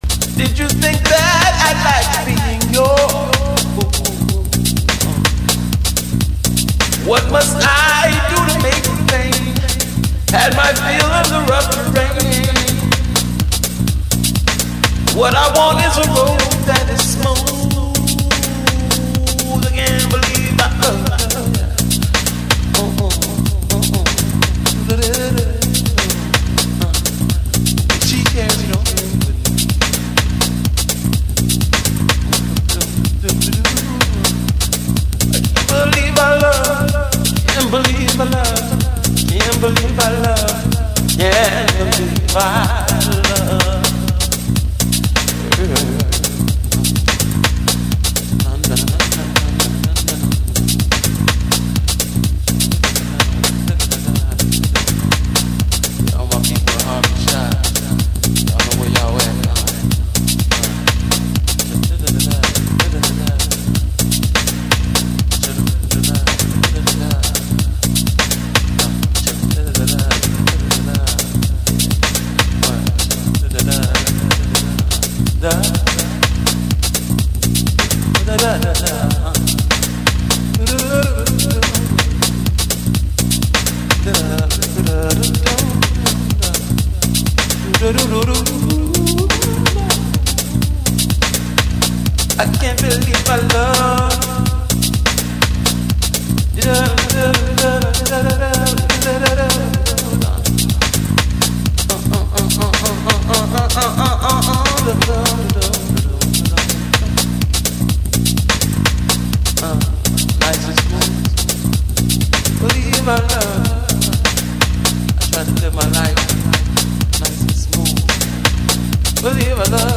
Deep house fans must not miss it!